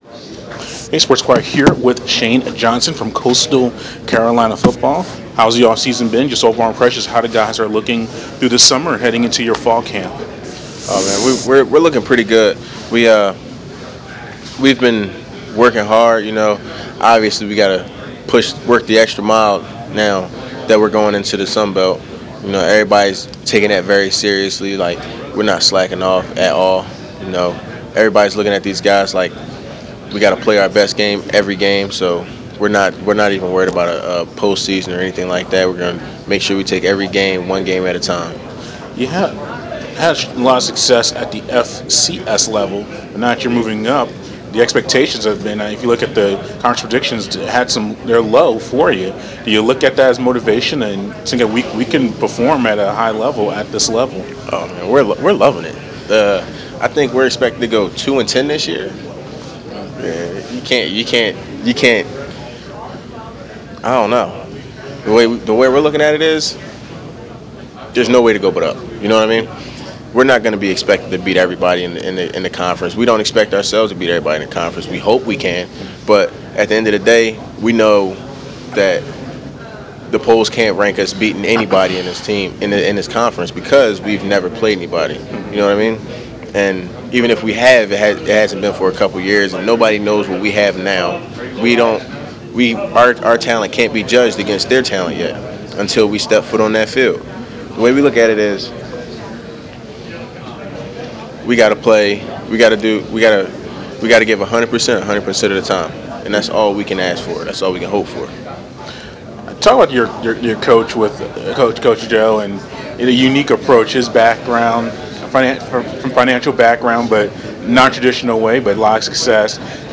interview
at the Sun Belt Media Day in New Orleans earlier this summer